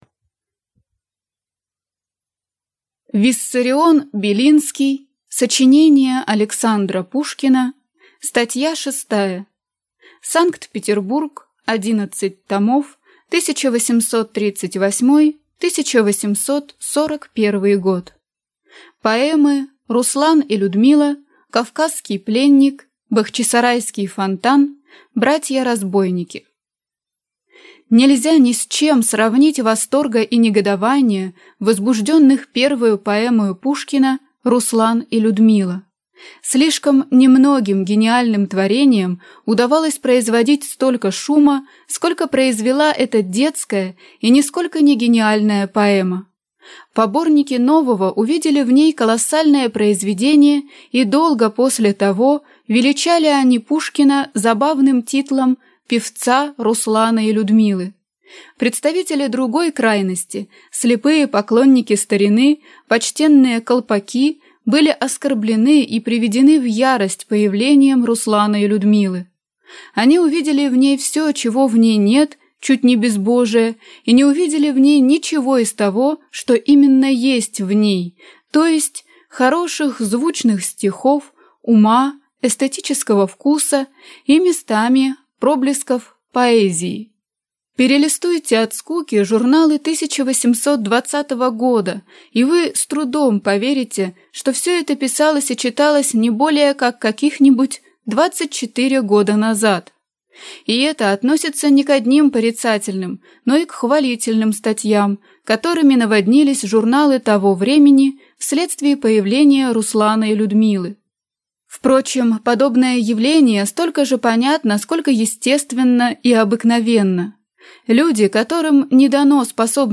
Аудиокнига Сочинения Александра Пушкина. Статья шестая | Библиотека аудиокниг